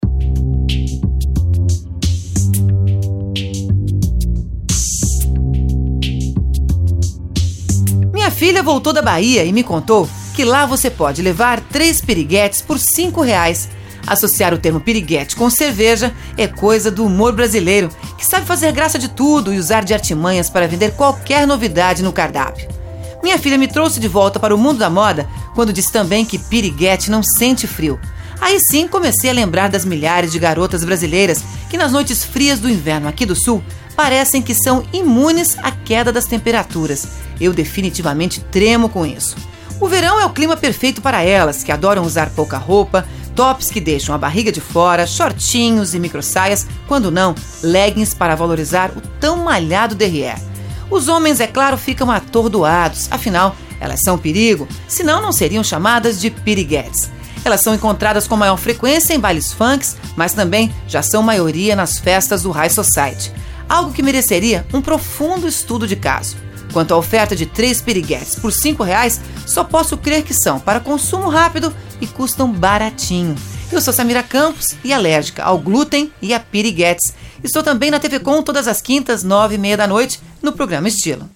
Confira o comentário bem humorado
sobre as piriquetes na rádio Itapema FM - SC.